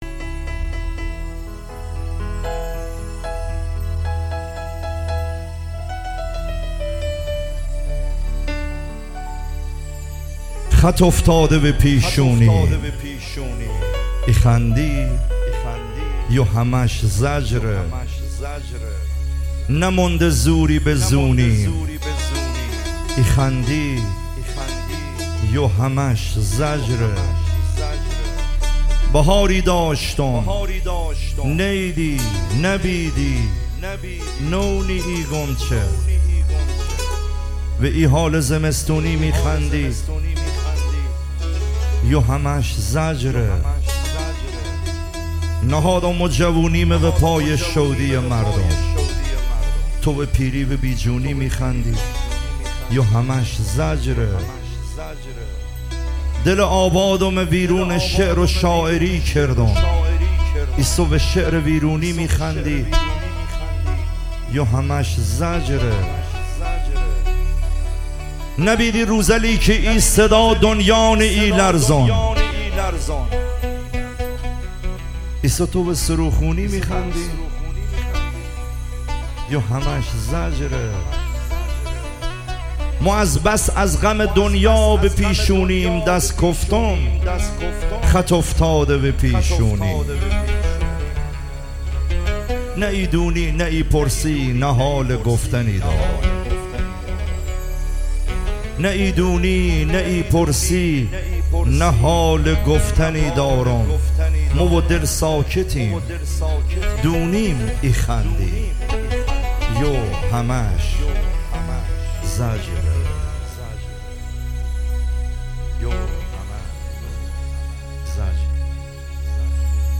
محلی لری